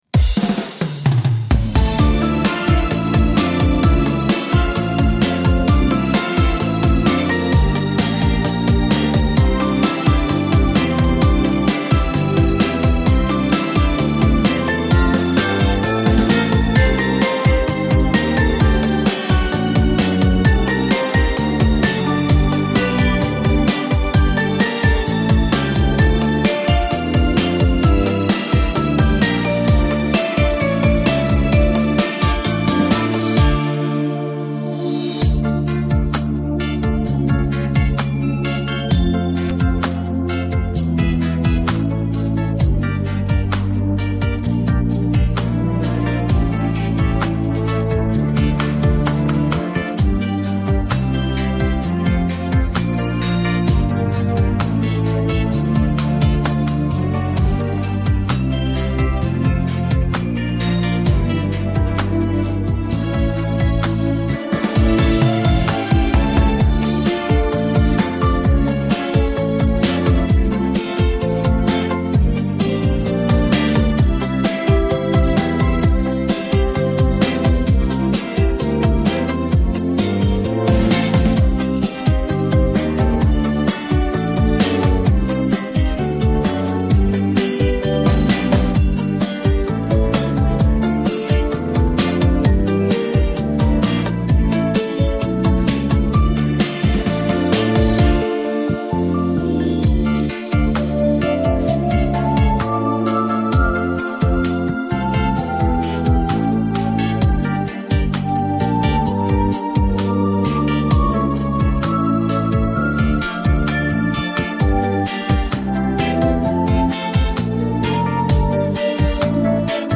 Детская песня
караоке
минусовка